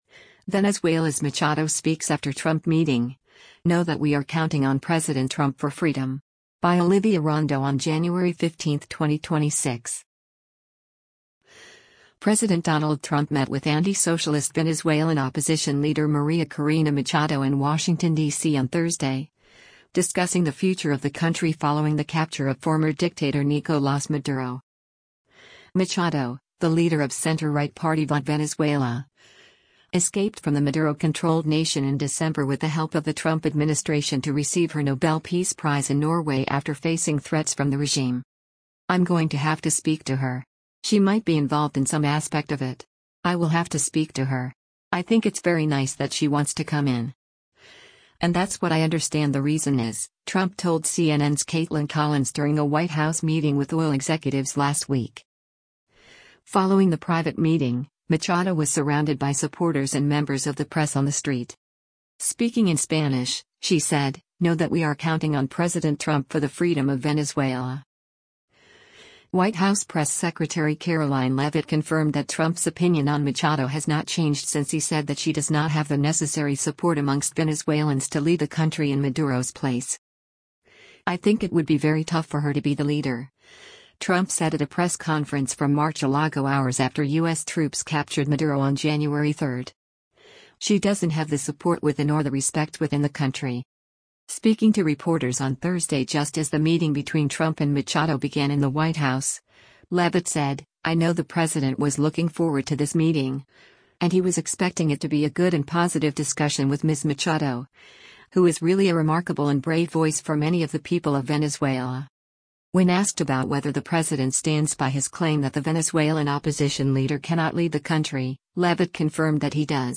Following the private meeting, Machado was surrounded by supporters and members of the press on the street.
Speaking in Spanish, she said, “Know that we are counting on President Trump for the freedom of Venezuela.”